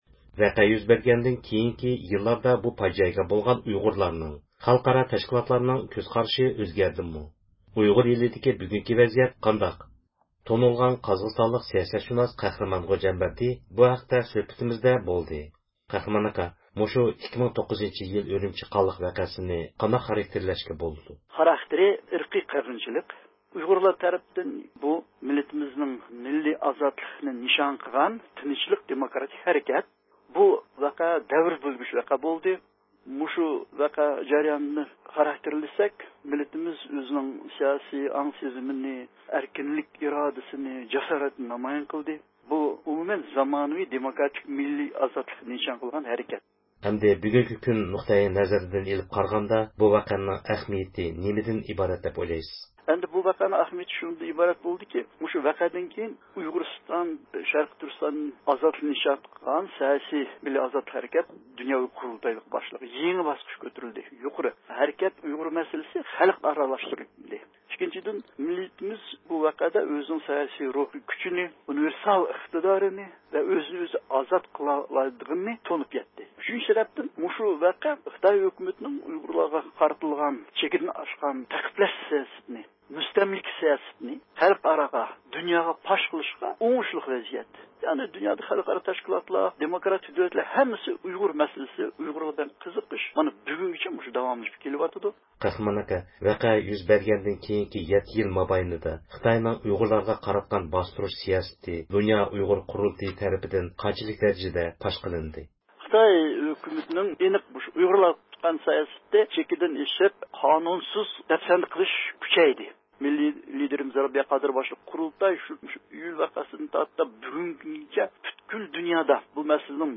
بىز مەزكۇر ئىلمىي مۇھاكىمە يىغىنى داۋاملىشىۋاتقان پەيتتە زىيارىتىمىزنى قوبۇل قىلغان دۇنيا ئۇيغۇر قۇرۇلتىيى ئىجرائىيە كومىتېتىنىڭ رەئىسى دولقۇن ئەيسا يىغىن ھەققىدە قىسقىچە توختالدى.
دۇنيا ئۇيغۇر قۇرۇلتىيىنىڭ رەئىسى رابىيە قادىر خانىم زىيارىتىمىزنى قوبۇل قىلىپ يىغىندىن ئۆزىنىڭ مەمنۇن بولغانلىقىنى بىلدۈردى.